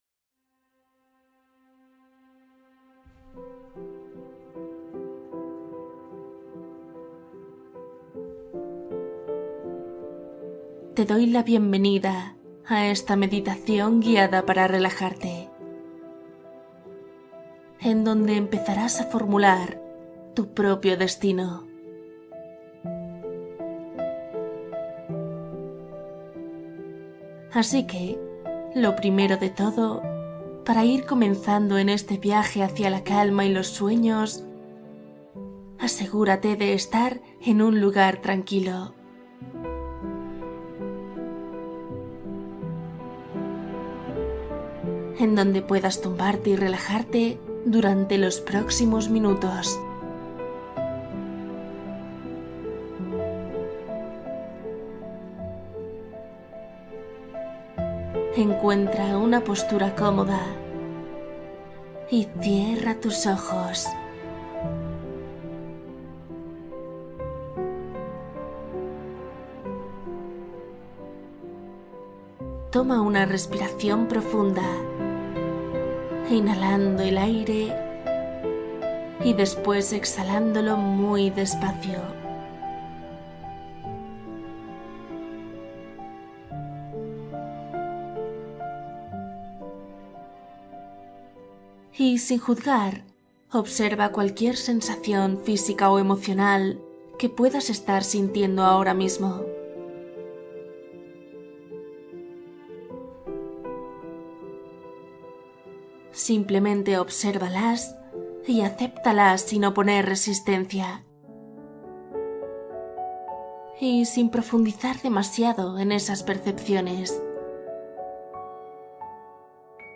Crear tu camino: una meditación de intención consciente